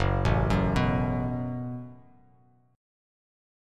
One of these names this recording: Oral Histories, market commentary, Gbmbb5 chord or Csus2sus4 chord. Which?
Gbmbb5 chord